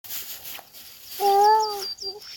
обыкновенная овсянка, Emberiza citrinella
Administratīvā teritorijaNeretas novads
СтатусПоёт
Примечания/redzēta viena, dzirdētas 2 sasaucoties